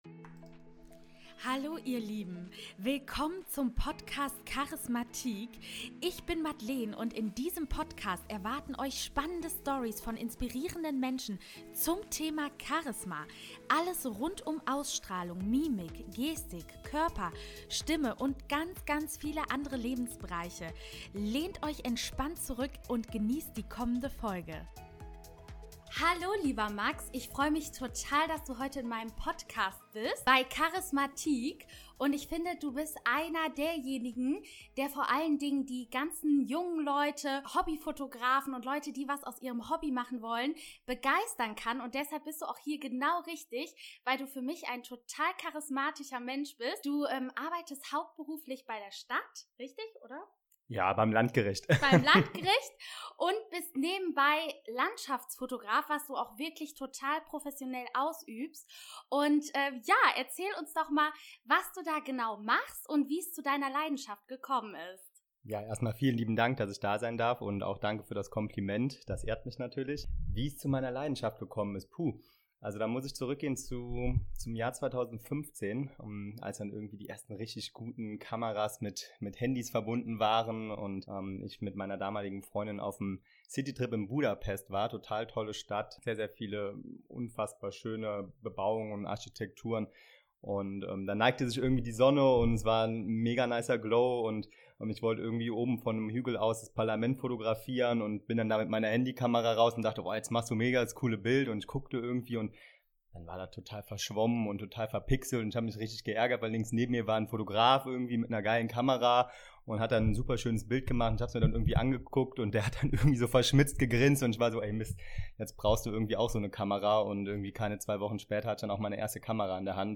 Einmal um die Welt - Einmal Nine-to-five - Interview